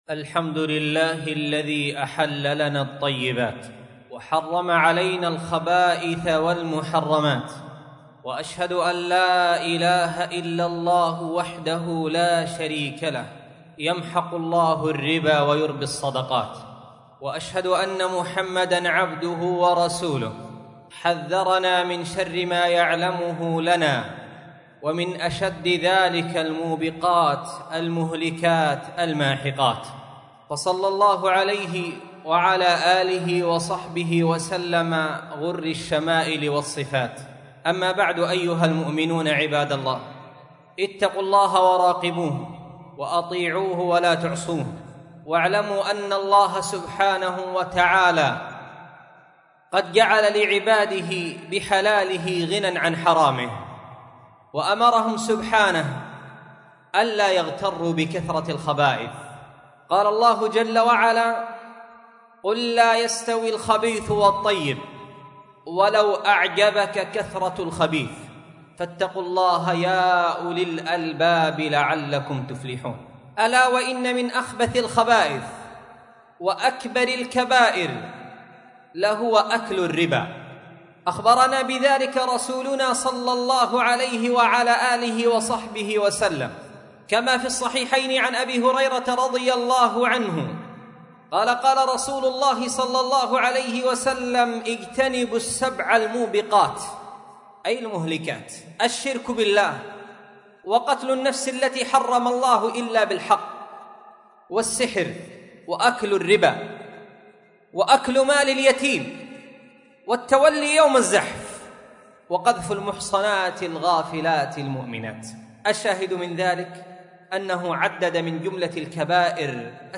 مسجد درة عدن / مدينة عدن حرسها الله 27 / جماد الأولى 1446